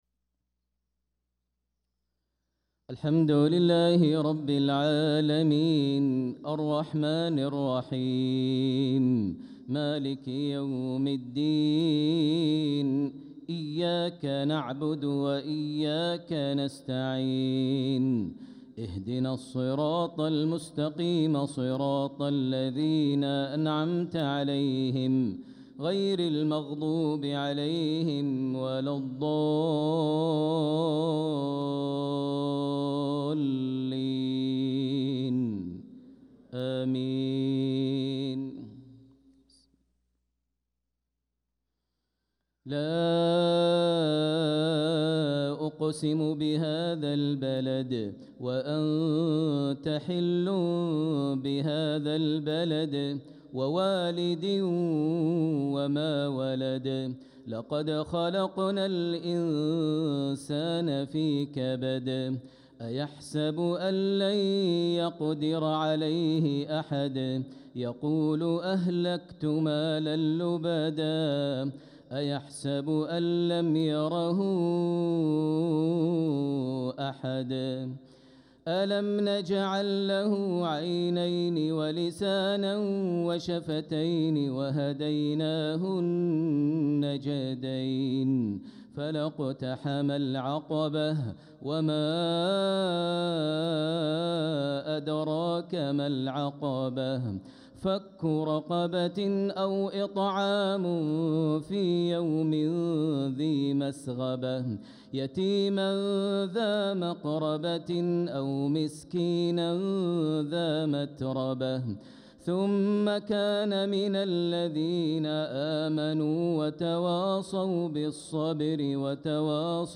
صلاة المغرب للقارئ ماهر المعيقلي 22 ربيع الأول 1446 هـ
تِلَاوَات الْحَرَمَيْن .